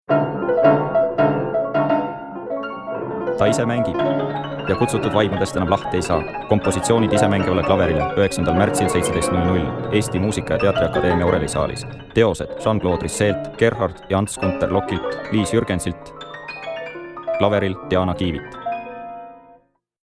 Radioreklame
isemangiv_klaver.mp3